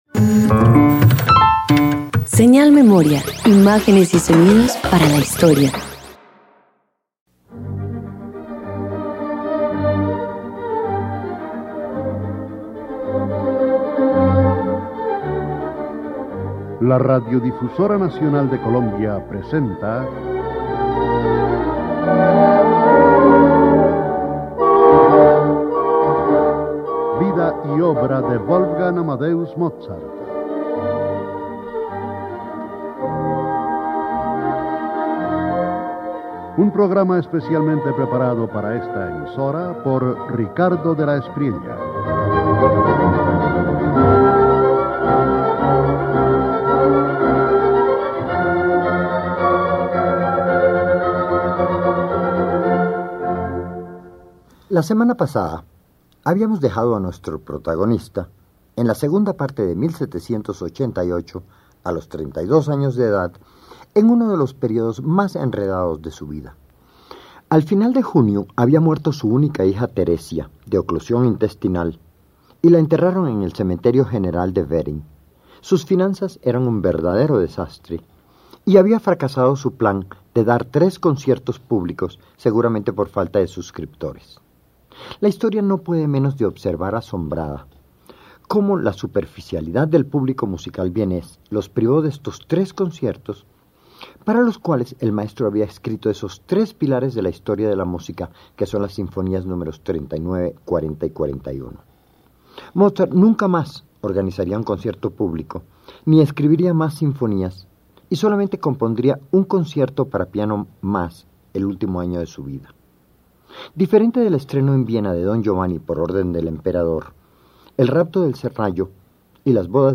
290 Tríos para cuerdas_1.mp3